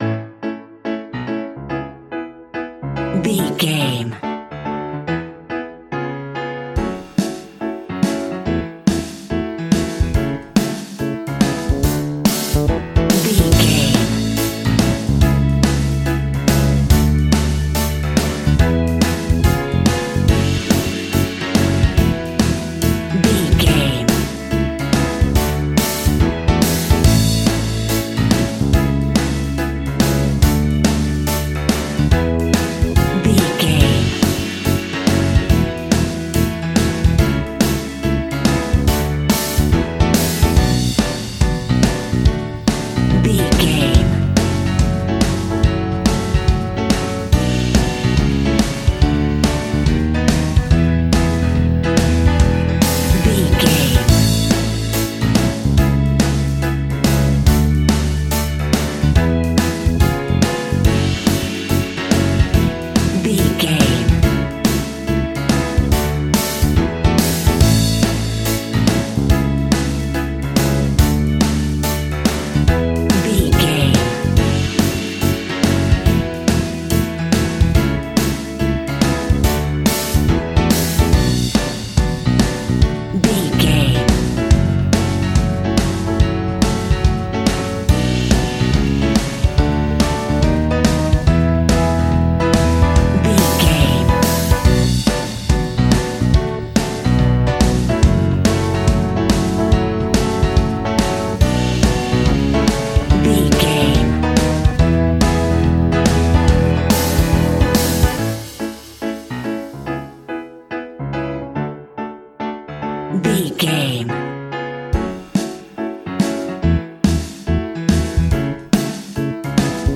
Uplifting
Ionian/Major
pop rock
indie pop
fun
energetic
cheesy
acoustic guitars
drums
bass guitar
electric guitar
piano
organ